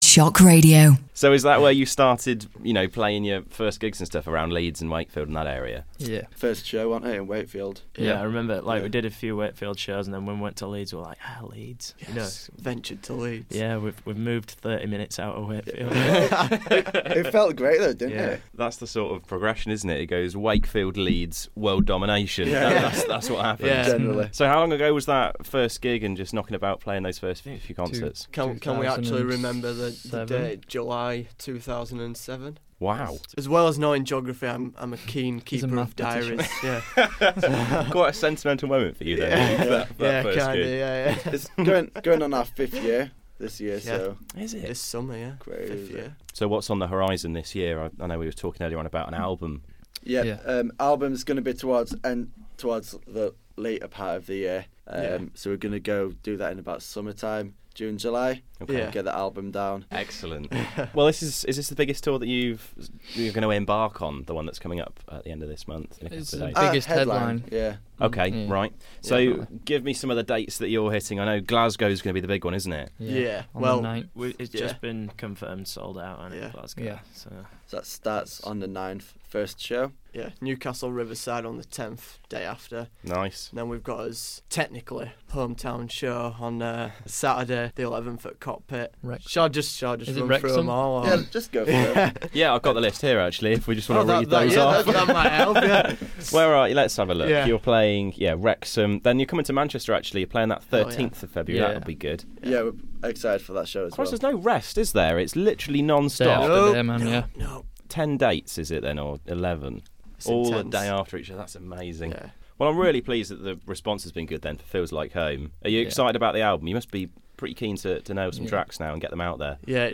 Feb12 - The Headstarts interview